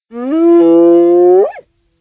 c_hyena_slct.wav